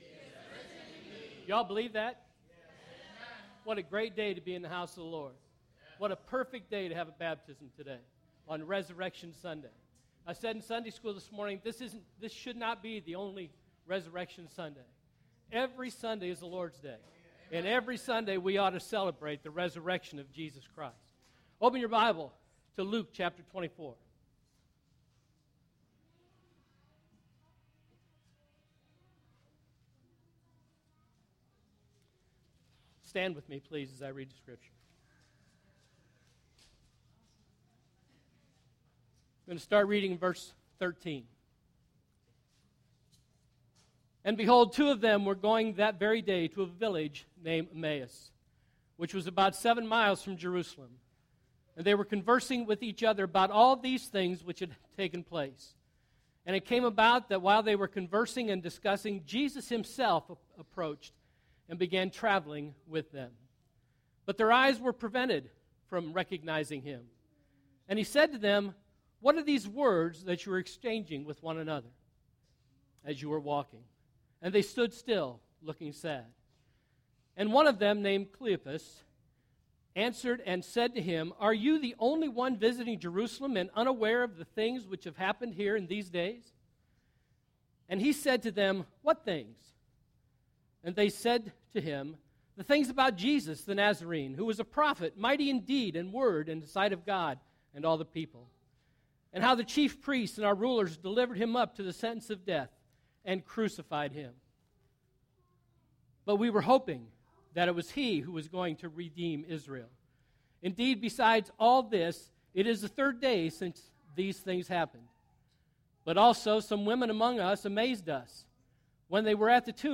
First Baptist Sermons